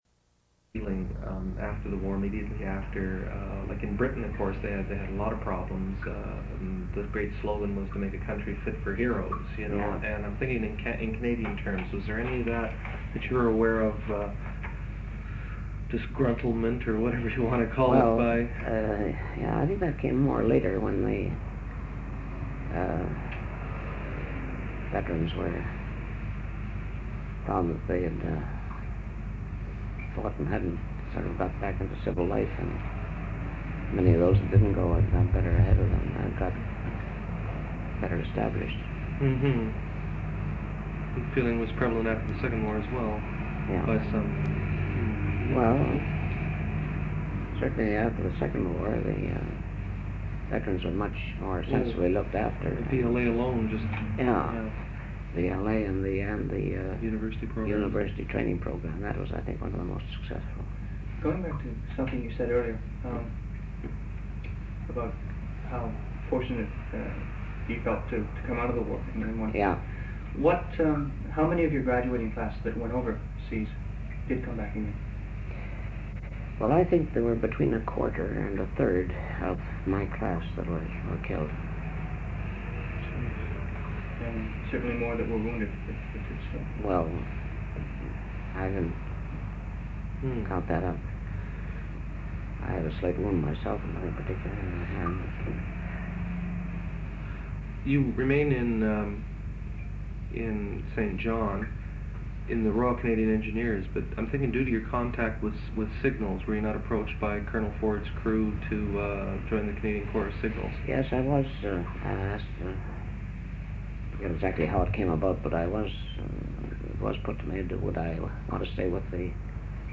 An interview/narrative of Eedson Louis Millard Burns's experiences during World War I. Lieutenant-General Burns, C.C., D.S.O., O.B.E., M.C. served with the Royal Canadian Engineers.